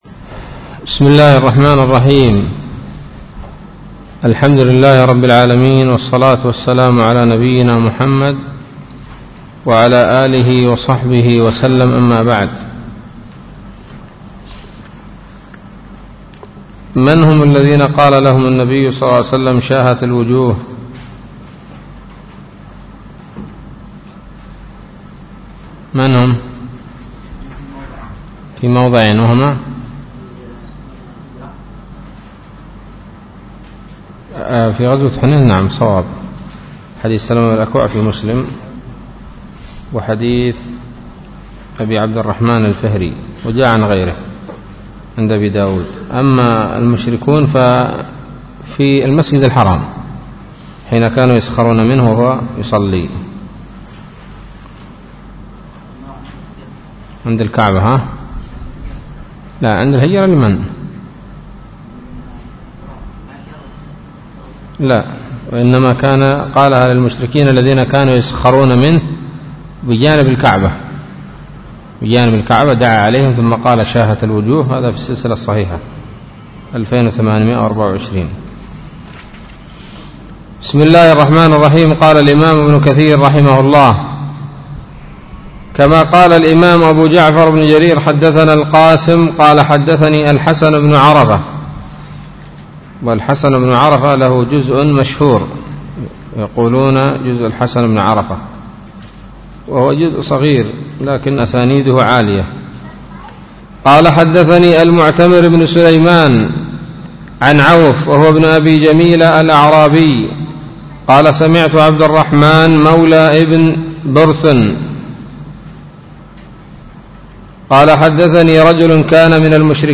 الدرس الحادي عشر من سورة التوبة من تفسير ابن كثير رحمه الله تعالى